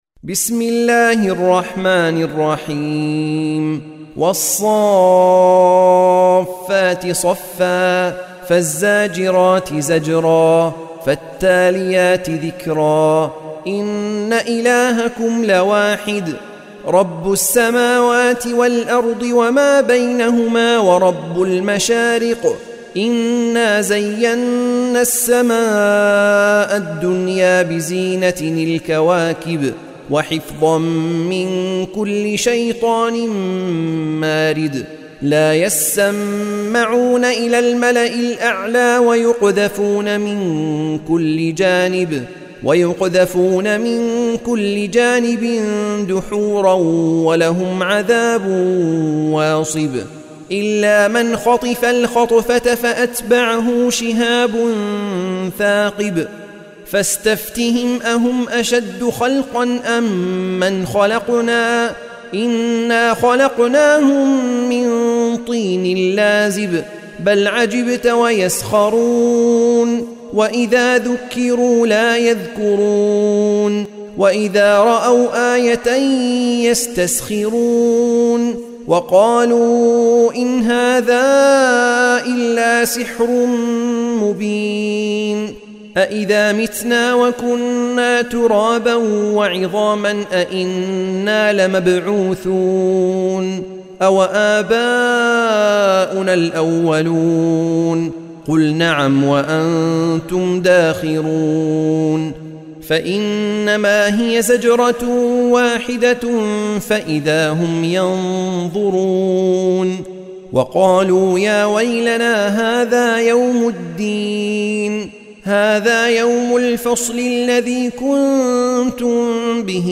سورة الصافات | القارئ